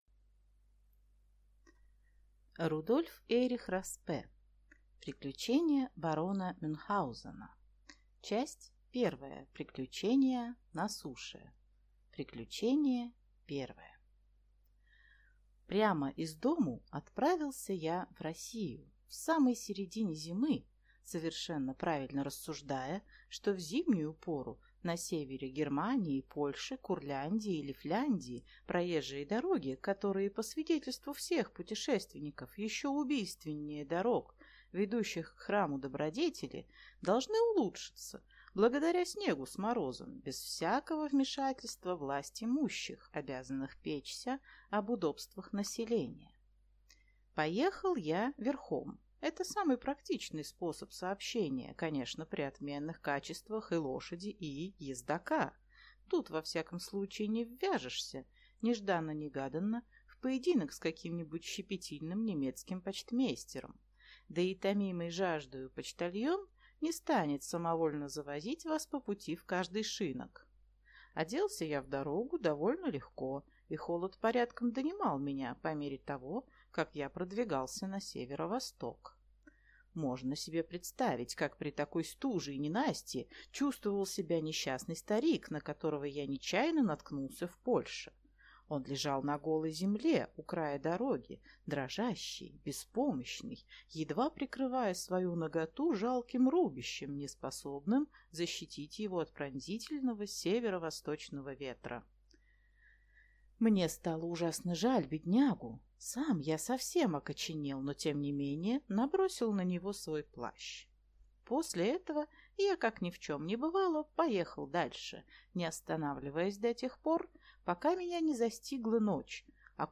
Аудиокнига Приключения барона Мюнхгаузена | Библиотека аудиокниг